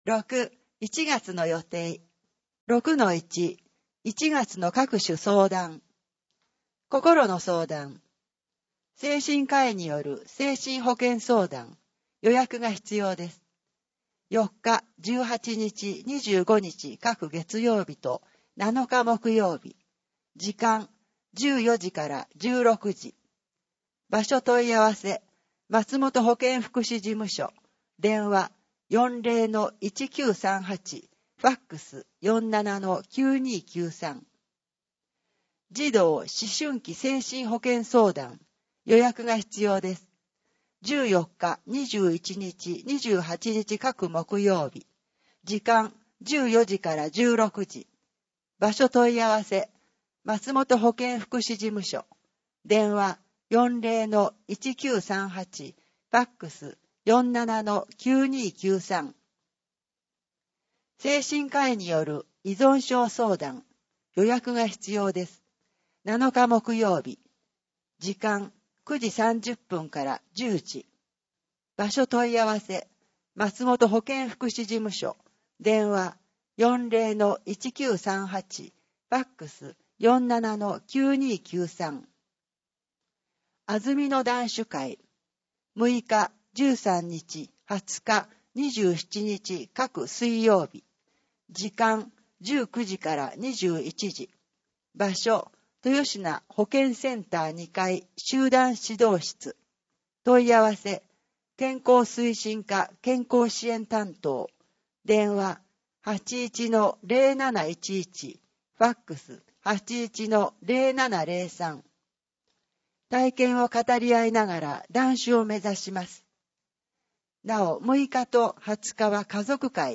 広報あづみの朗読版325号（令和2年12月23日発行) - 安曇野市公式ホームページ
「広報あづみの」を音声でご利用いただけます。この録音図書は、安曇野市中央図書館が制作しています。